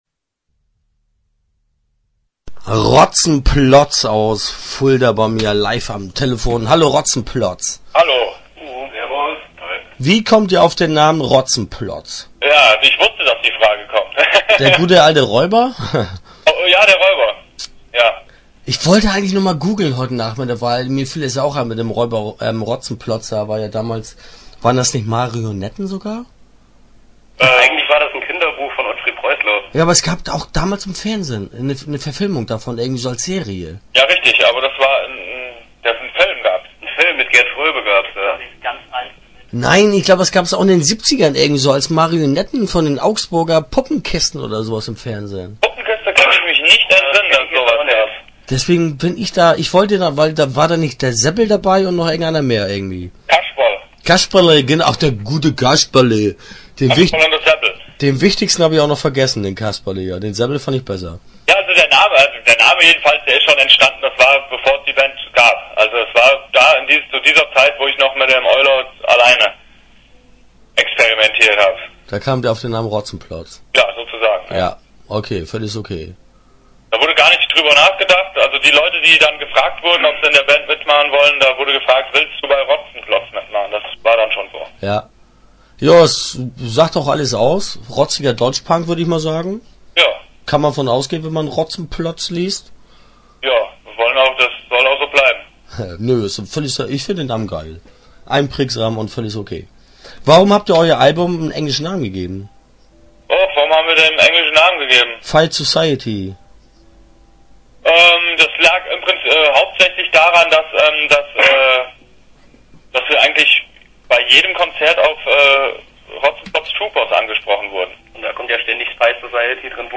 Interview Teil 1 (8:34)